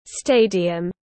Stadium /ˈsteɪ.di.əm/